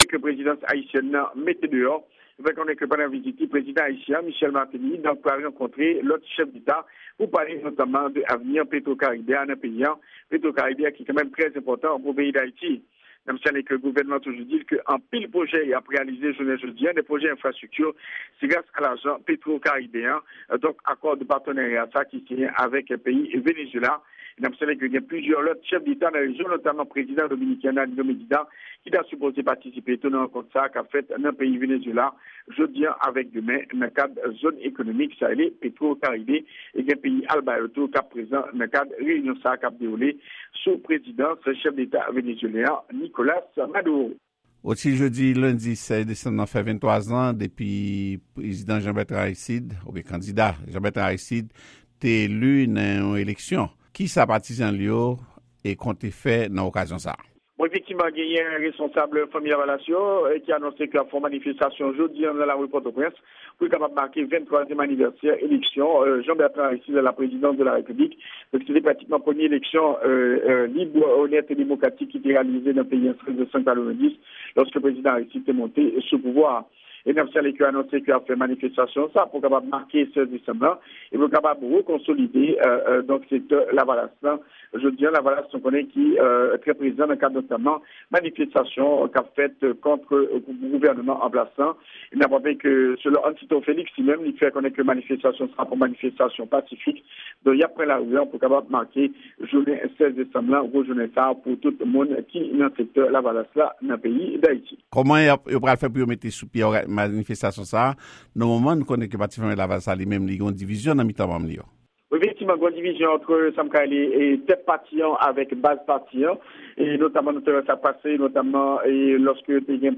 Yon repòtaj